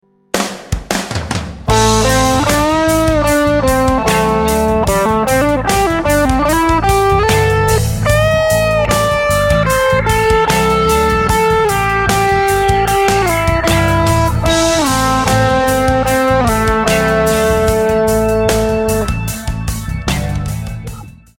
It can also be written as a small grace note or tab number which is shown in bar 2 when the D note (fret 7) slides quickly to E (fret 9).
Sexy Solo Slides